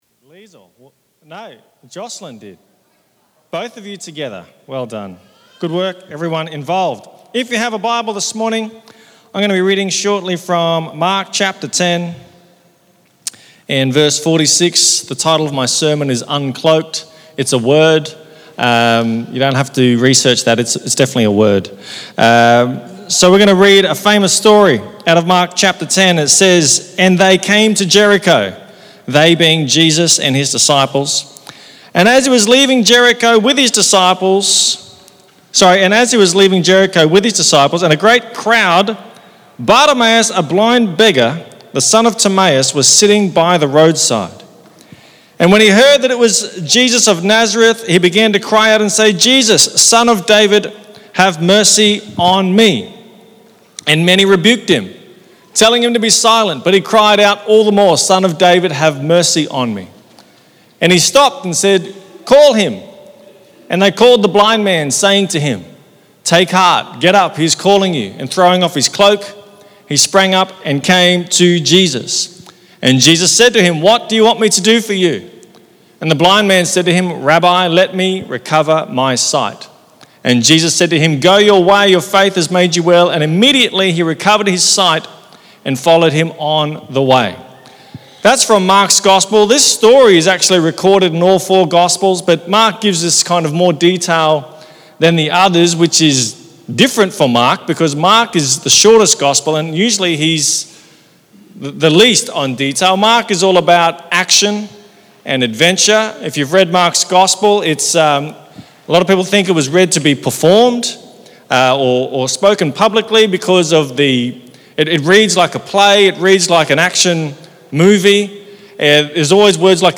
Sermons | Mackay Christian Family